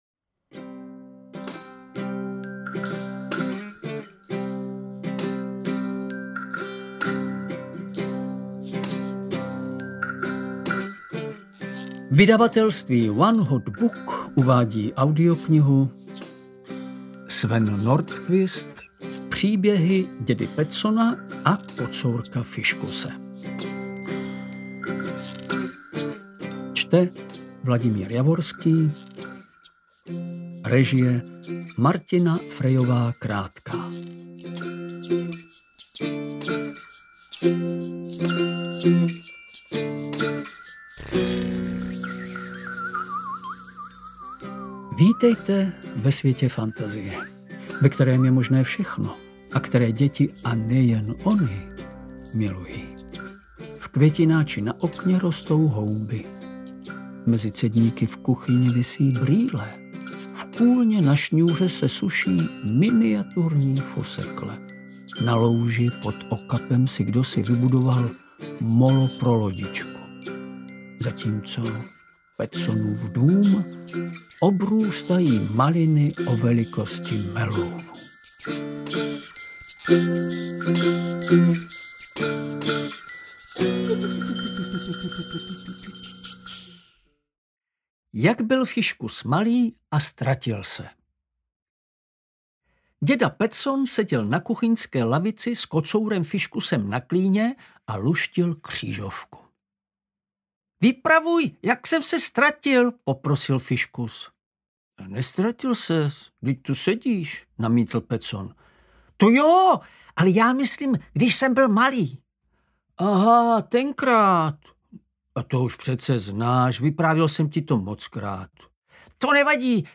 Interpret:  Vladimír Javorský
AudioKniha ke stažení, 3 x mp3, délka 1 hod. 20 min., velikost 74,1 MB, česky